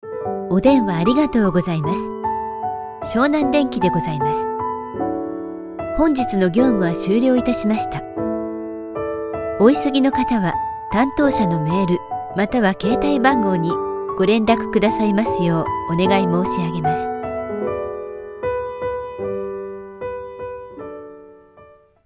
Ideal for corporate narration, IVR, animation, and commercials, she delivers professional voice over services with broadcast-level clarity and speed.
IVR